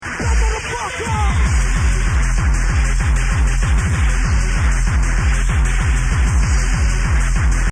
need id on this hardstyle song
This isnt Hardstyle, this is hardcore...